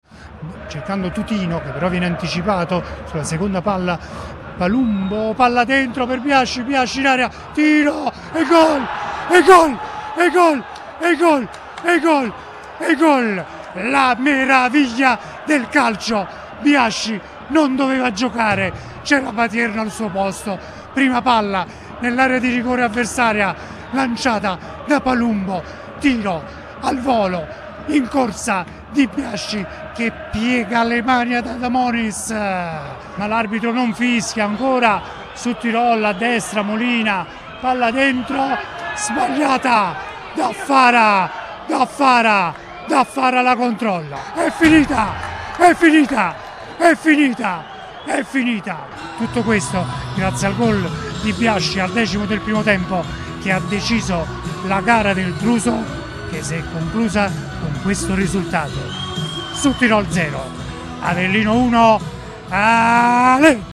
ASCOLTA LE EMOZIONI DI SUDTIROL-AVELLINO